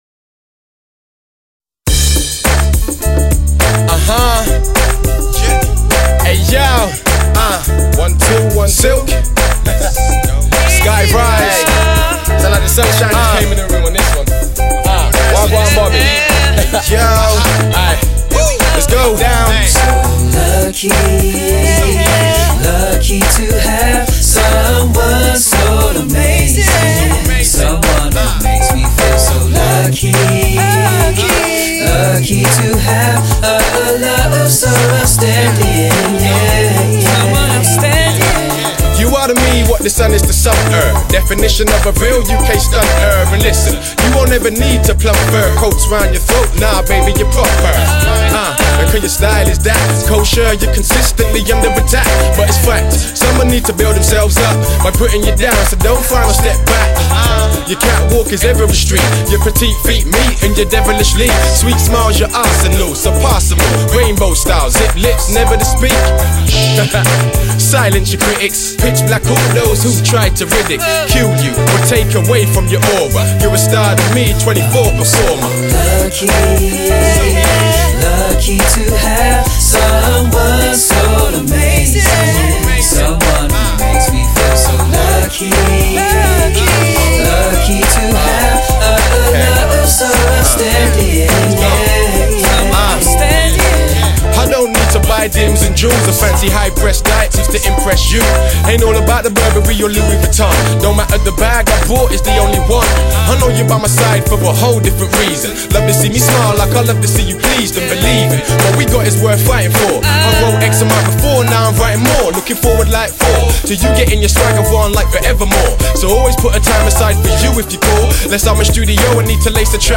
RnB music